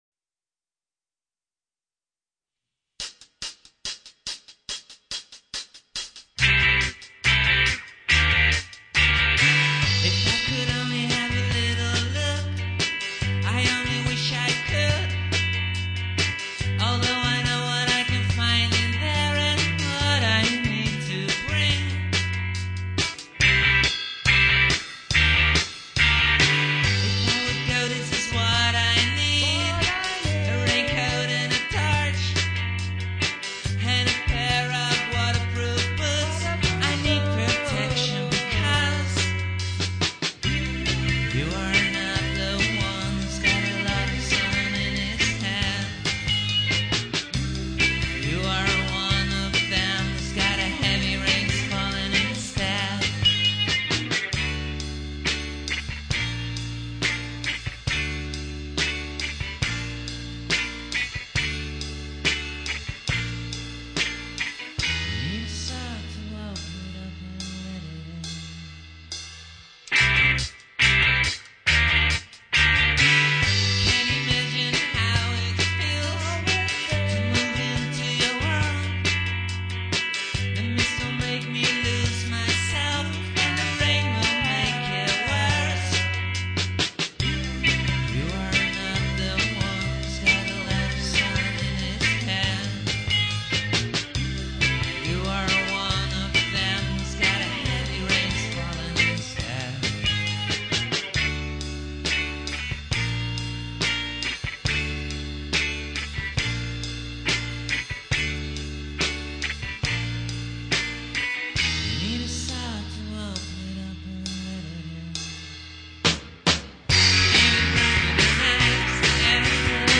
where: recorded at CMA (Amsterdam)